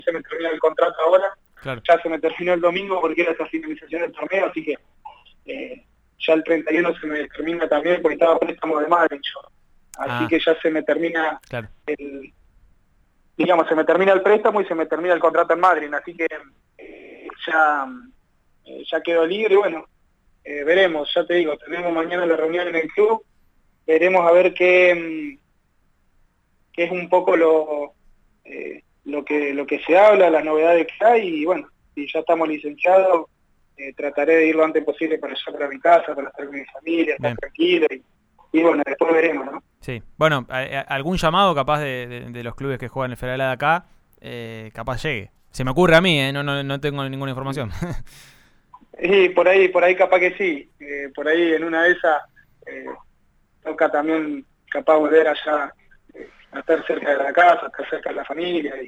en diálogo con «Subite al Podio» de Río Negro Radio